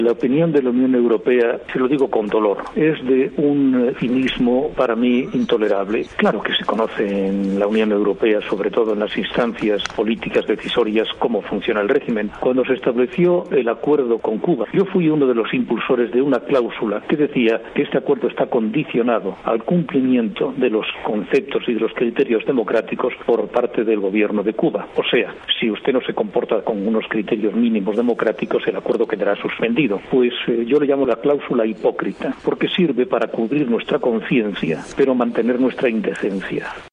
Declaraciones del eurodiputado español Javier Nart